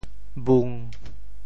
潮州发音 潮州 bhung1